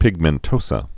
(pĭgmĕn-tōsə, -mən-)